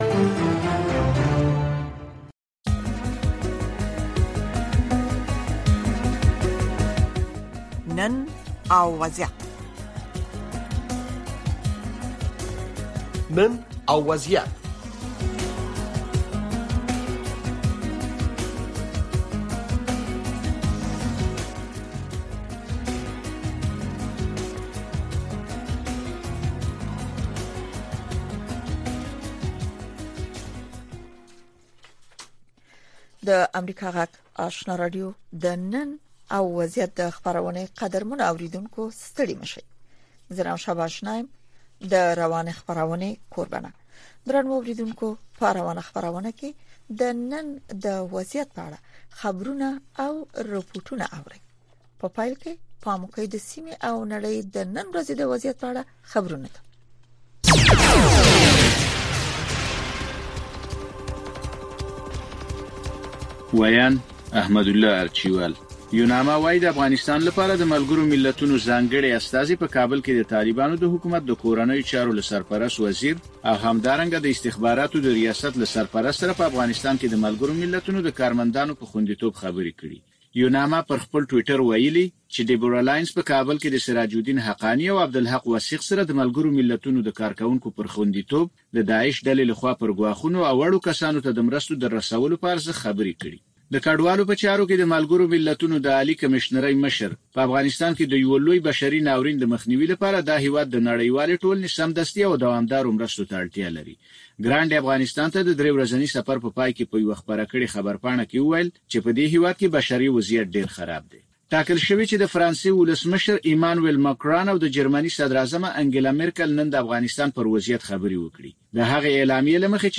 د نړۍ سیمې او افغانستان په روانو چارو او د نن په وضعیت خبرونه، راپورونه، مرکې او تحلیلونه